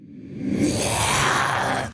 ID_scream.ogg